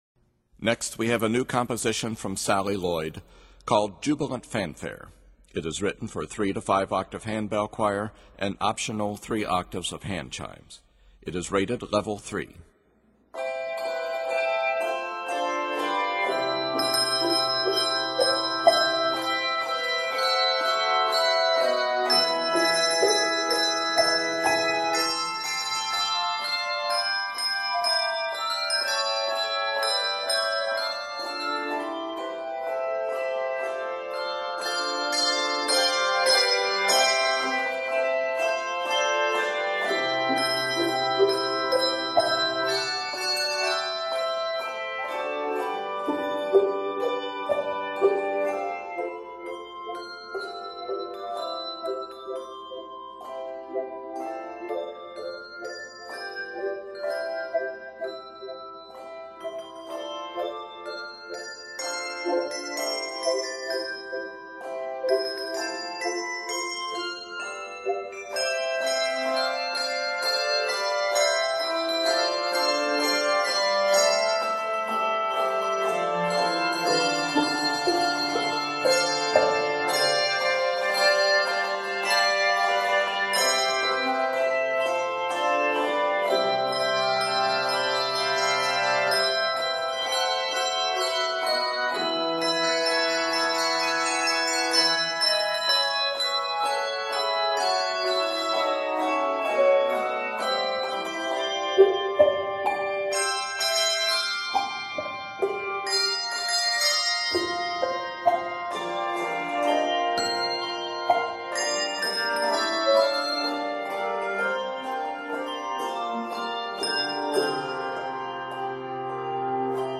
is composed in C Major and F Major and is 76 measures